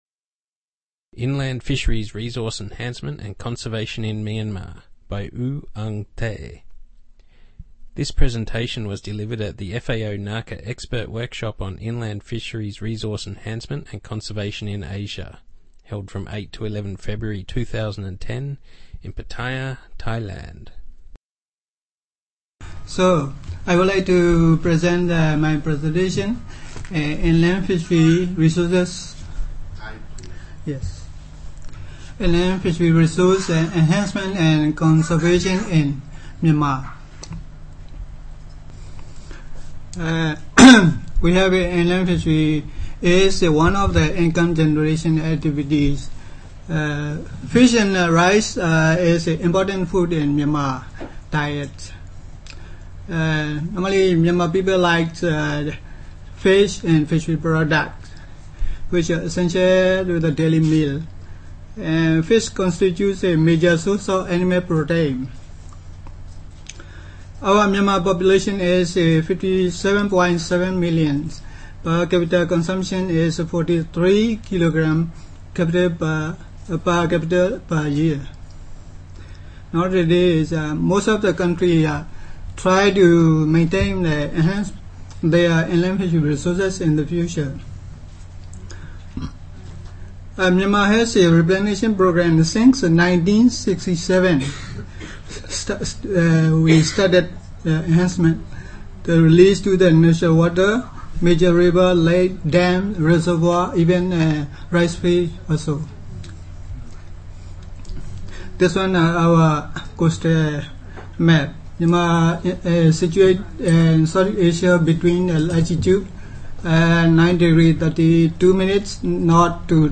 Presentation on inland fisheries resource enhancement and conservation in Myanmar